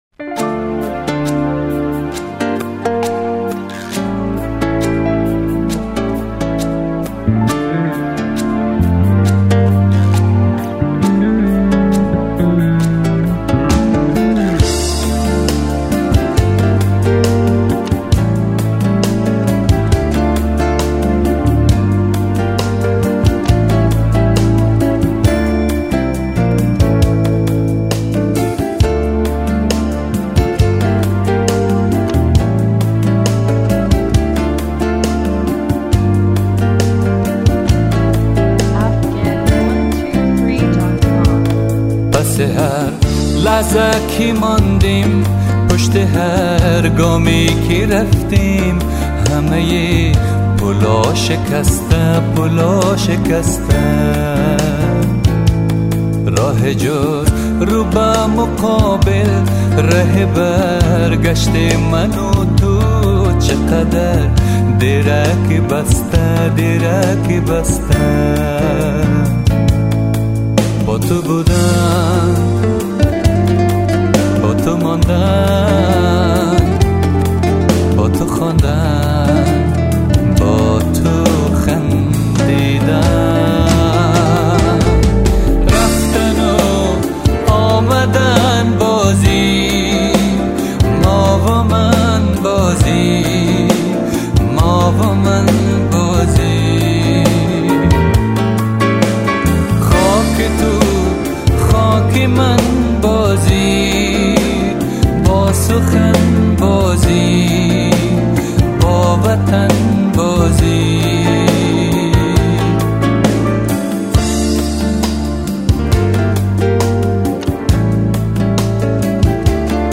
Мусиқа ва тарона